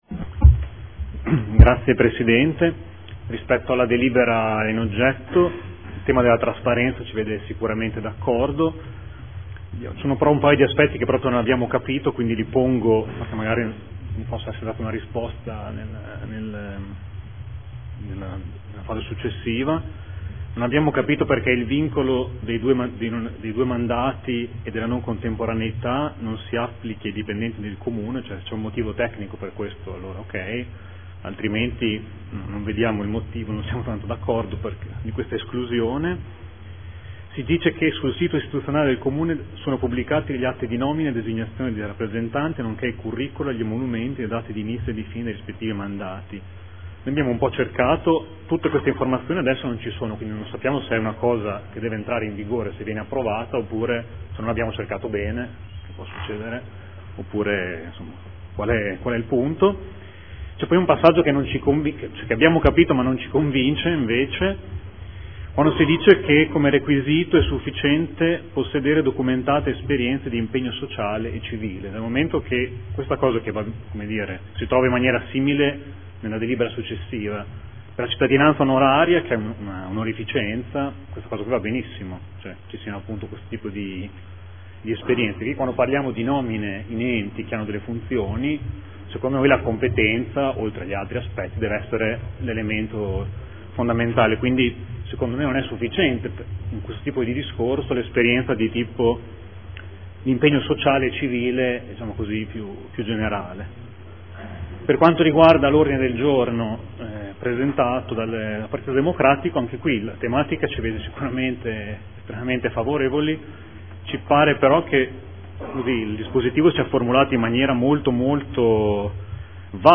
Mario Bussetti — Sito Audio Consiglio Comunale
Seduta del 05/02/2015 Dibattito. Definizione degli indirizzi per la nomina e la designazione dei rappresentanti nel Comune presso Enti, Aziende, Istituzioni e Società partecipate